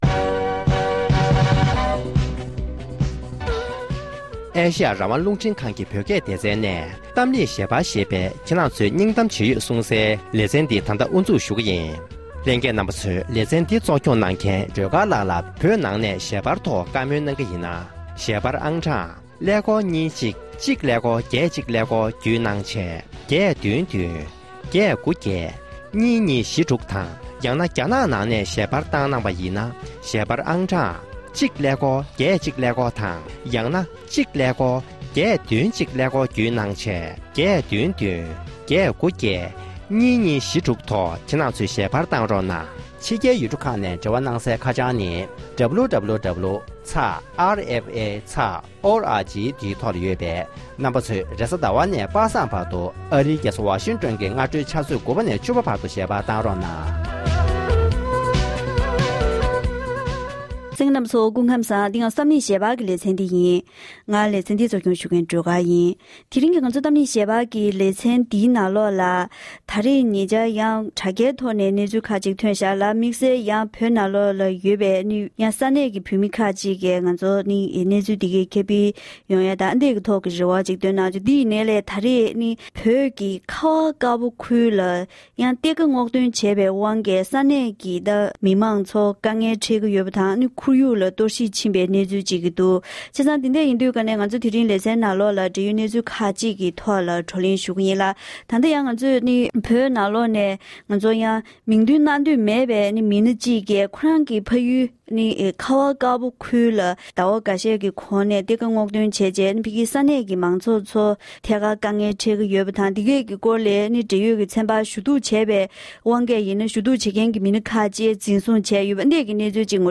གཏམ་གླེང་ཞལ་པར་གྱི་ལེ་ཚན